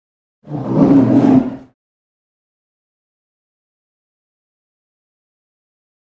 На этой странице собраны звуки полярного медведя – мощные рыки, ворчание и шаги по снегу.
Грозный рык белого медведя